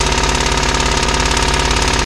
Jet Alarm Motor Pulses From Single Engine Jet